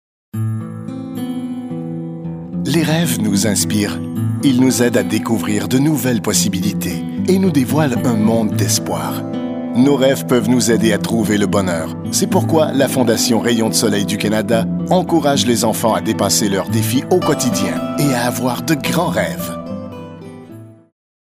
Voix Hors Champ narrateur professionnel producteur radio
Sprechprobe: eLearning (Muttersprache):